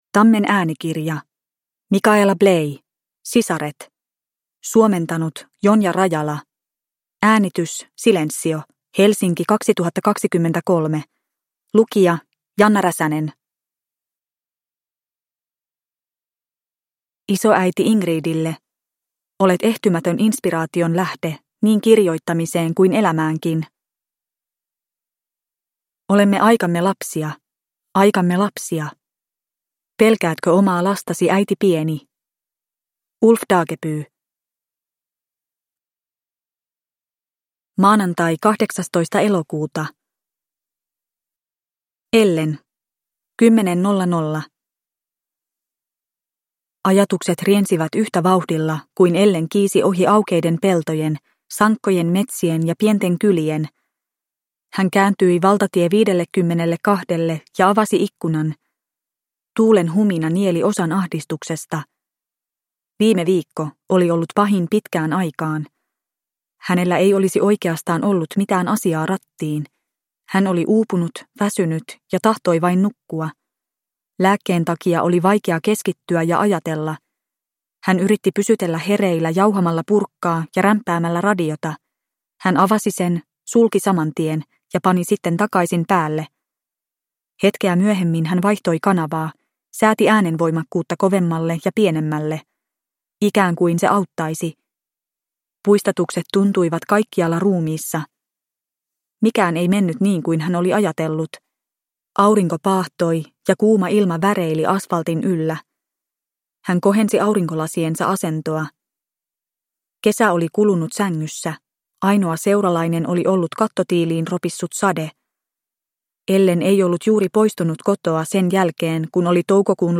Sisaret – Ljudbok – Laddas ner